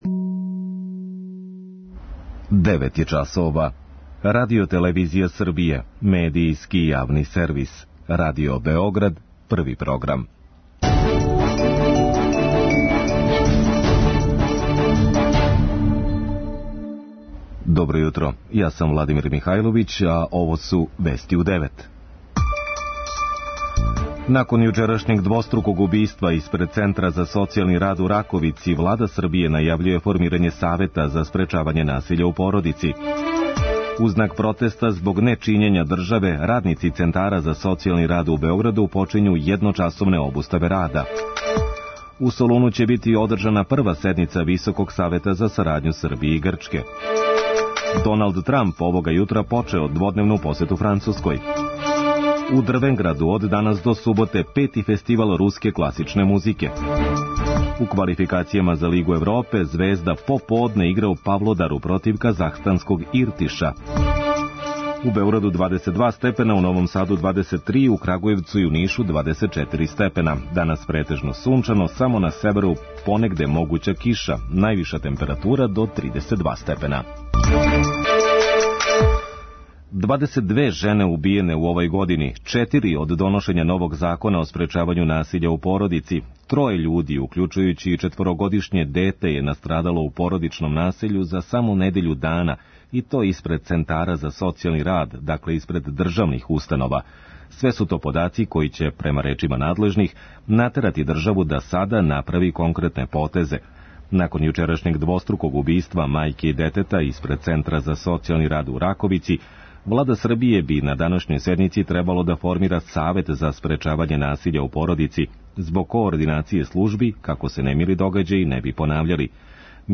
преузми : 3.95 MB Вести у 9 Autor: разни аутори Преглед најважнијиx информација из земље из света.